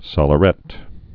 (sŏlə-rĕt)